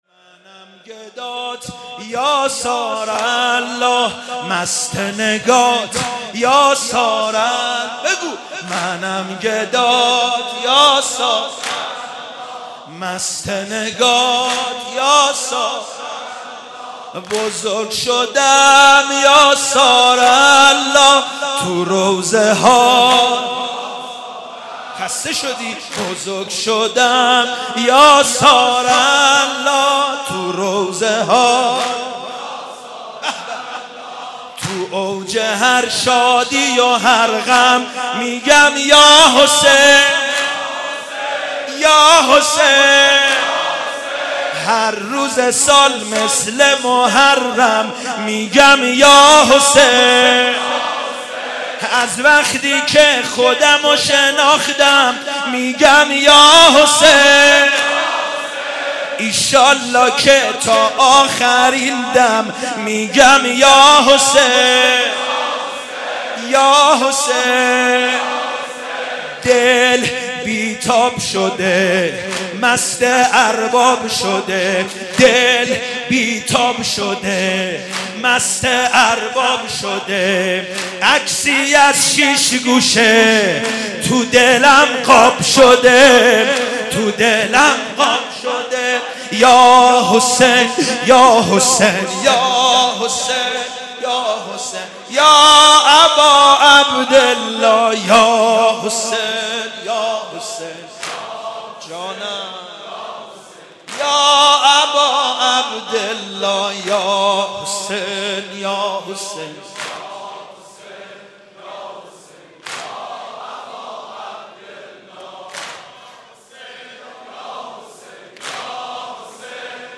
شب تاسوعا محرم 93